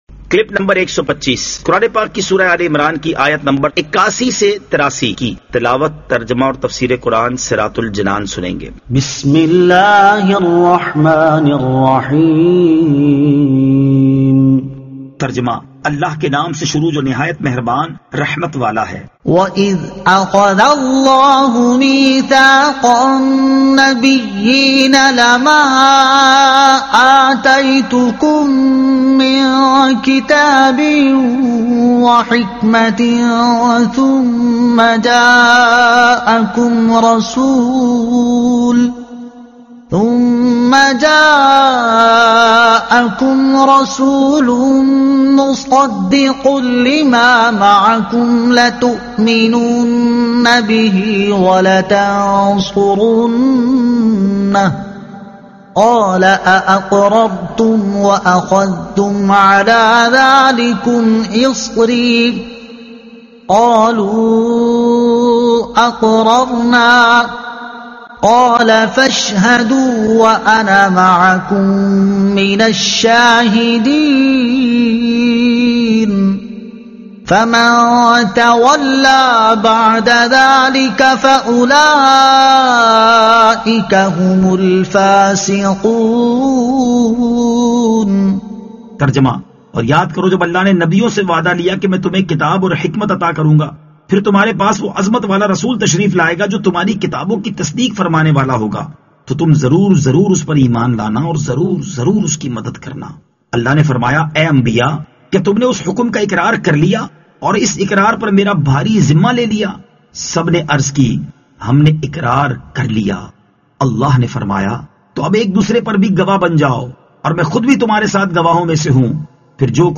Surah Aal-e-Imran Ayat 81 To 83 Tilawat , Tarjuma , Tafseer